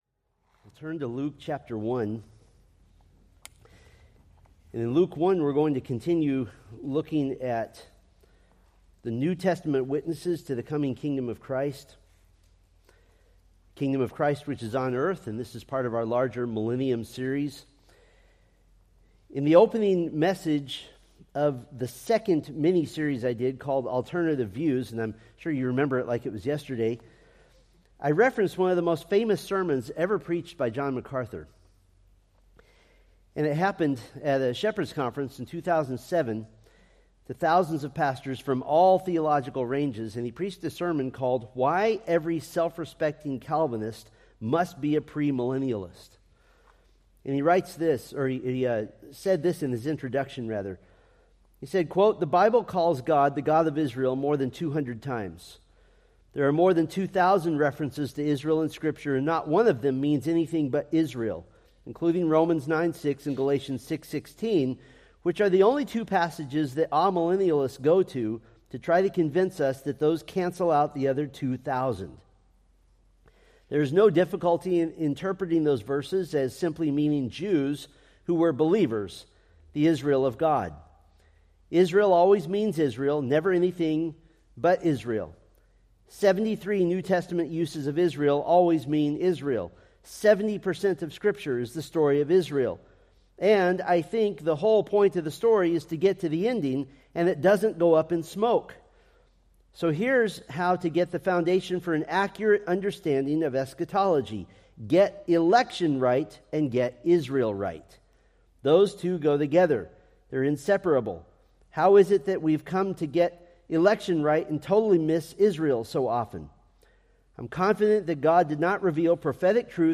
From the Millennium: New Testament Witnesses sermon series.